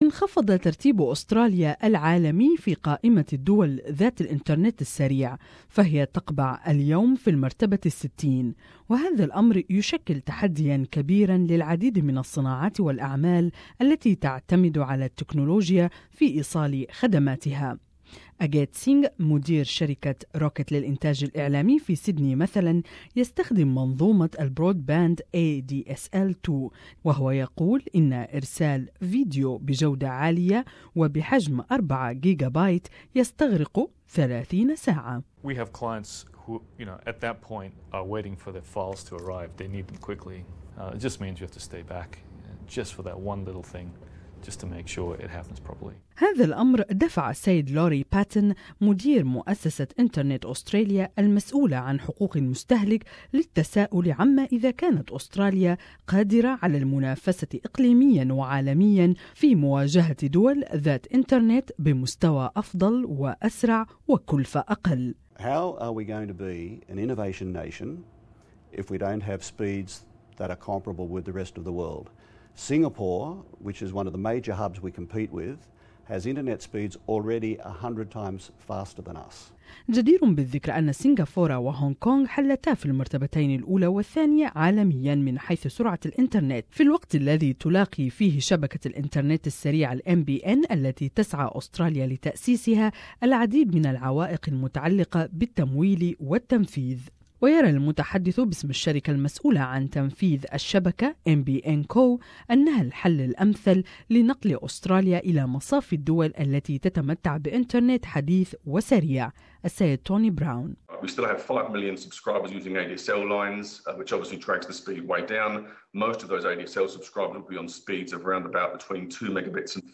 تقرير اخباري